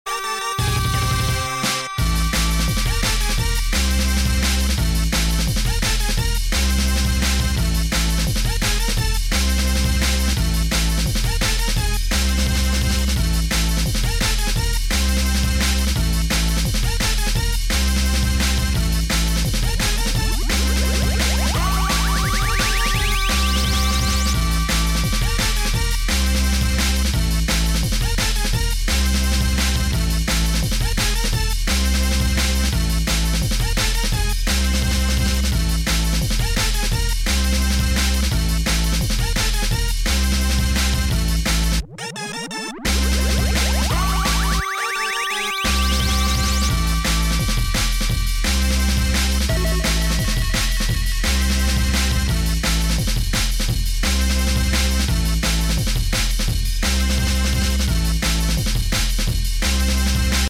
dnb track